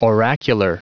Prononciation du mot oracular en anglais (fichier audio)
Prononciation du mot : oracular